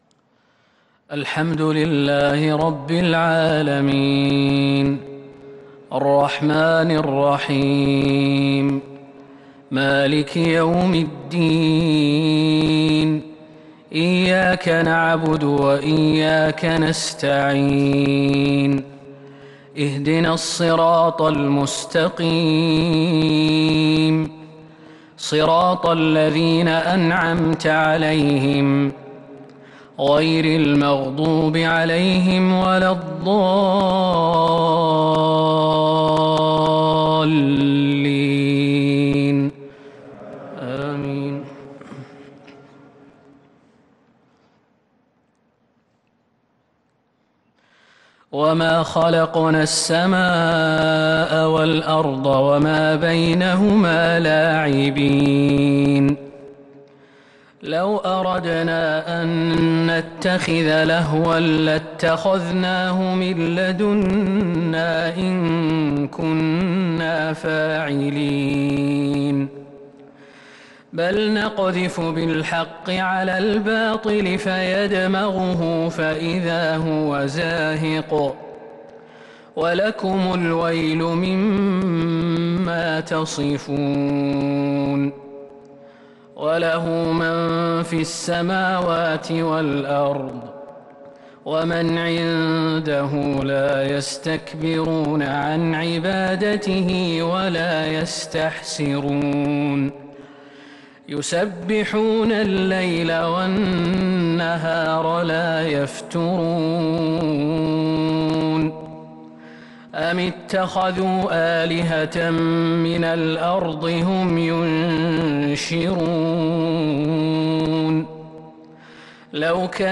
صلاة العشاء للقارئ خالد المهنا 7 محرم 1443 هـ
تِلَاوَات الْحَرَمَيْن .